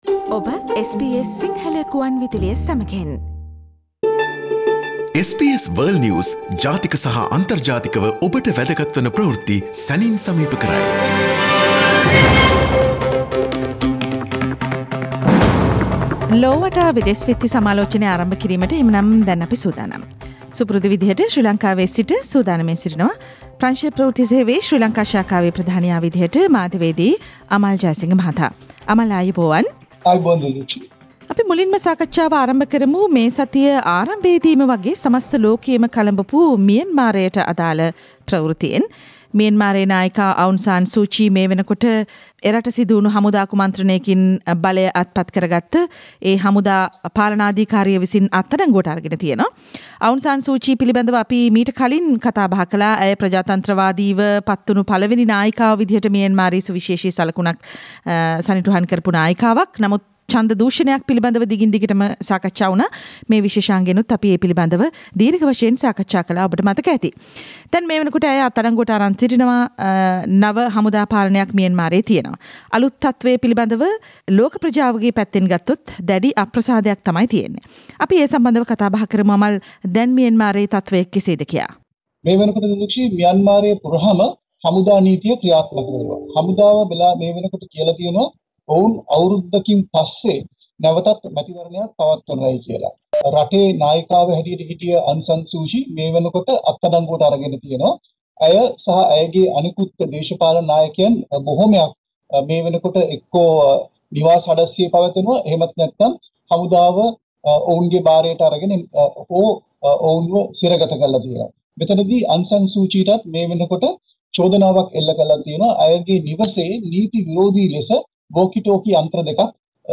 This weeks world news wrap brings your the updates of Myanmar military coup, what happened to Alexei and a new story never heard before about the corona vaccine